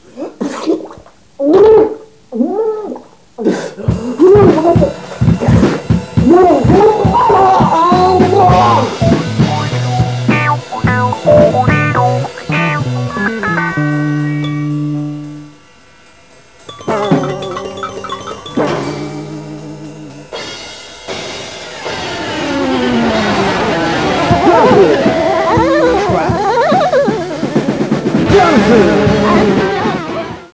vocals, lyrics, shitty guitar, flute, keyboards, other crap
guitar, backing vocals, song structure
drums